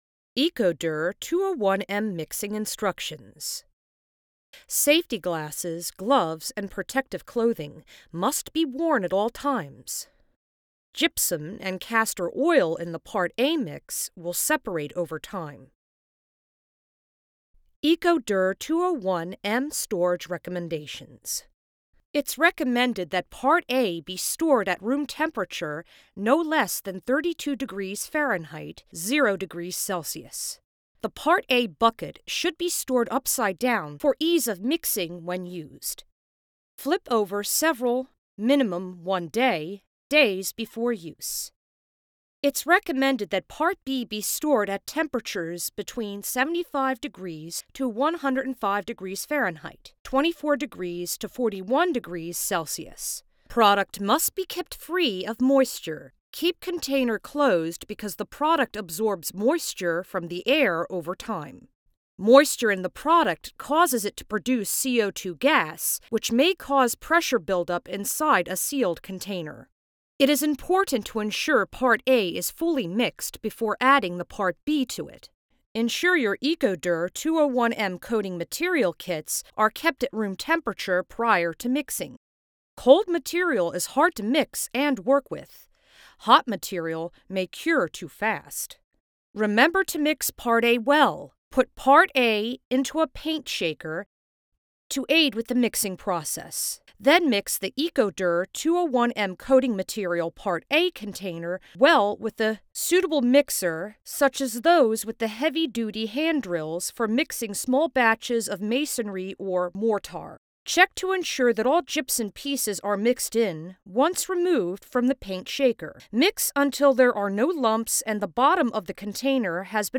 Female
English (North American)
Adult (30-50)
I can sound: authoritative, sexy, motherly, compassionate, creepy and I can a do handful of character voices.
Main Reel
All our voice actors have professional broadcast quality recording studios.